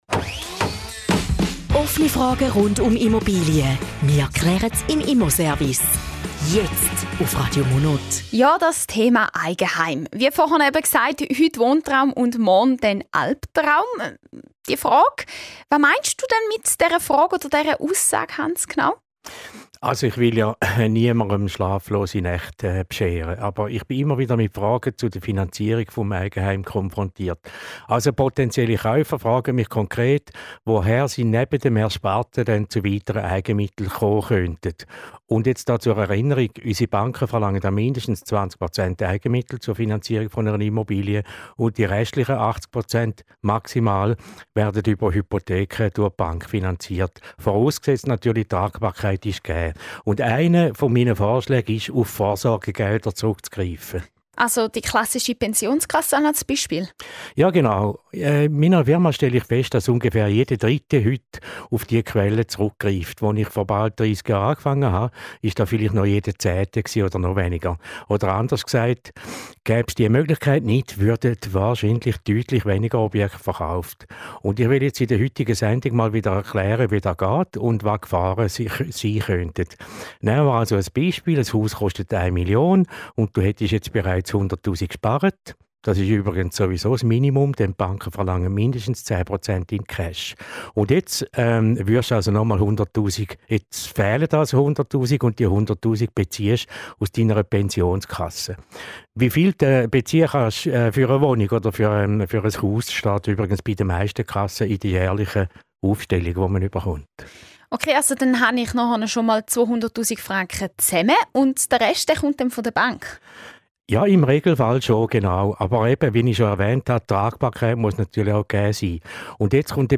Zusammenfassung des Interviews zum Thema "Wohnraum und Altersvorsorge":